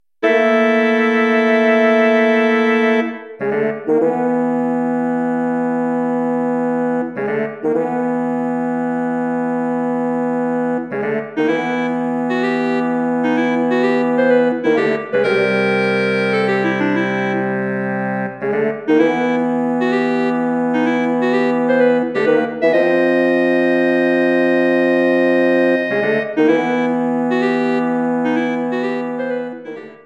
Bearbeitung für Bläserquintett
Besetzung: Flöte, Oboe, Klarinette (B), Horn (F), Fagott
Arrangement for woodwind quintet